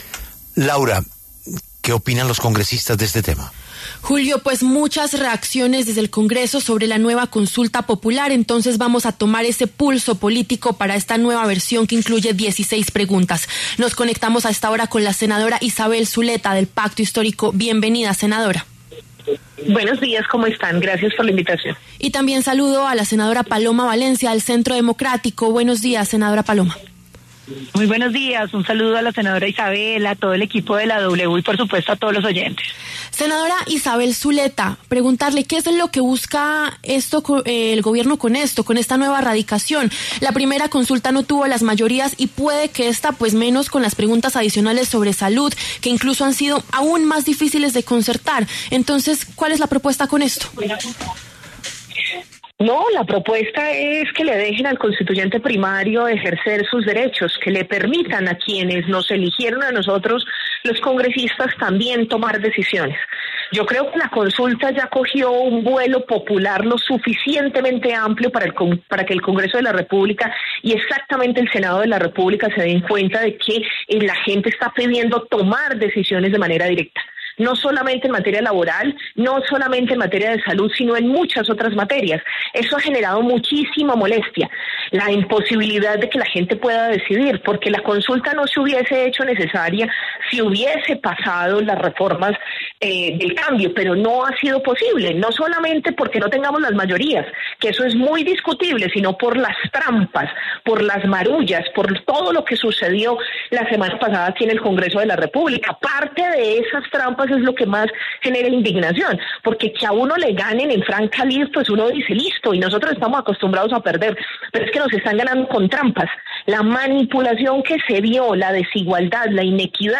Debate entre Zuleta y Valencia: ¿Gobierno presiona al Congreso con nueva consulta popular?
El Gobierno radicó este 19 de mayo una nueva versión de su consulta popular. Las senadoras Isabel Zuleta, del Pacto Histórico, y Paloma Valencia, del Centro Democrático, hablaron en La W.